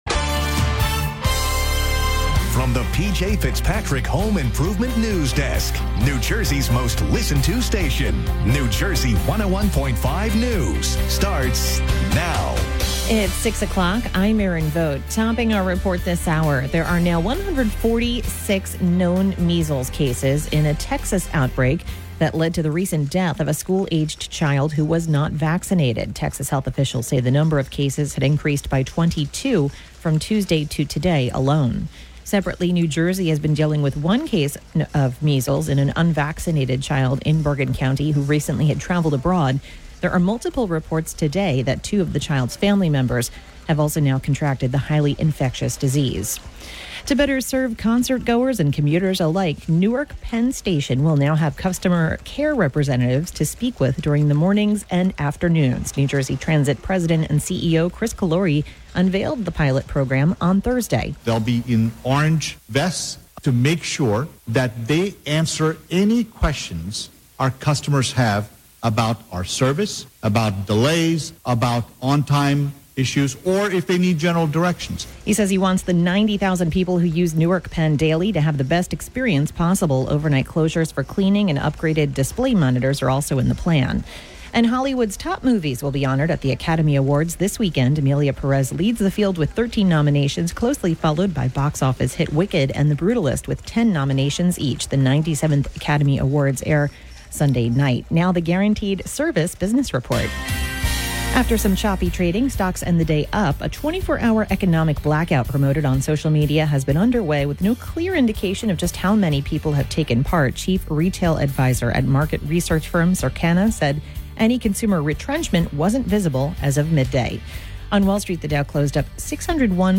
The latest New Jersey news and weather from New Jersey 101.5 FM, updated every hour, Monday through Friday.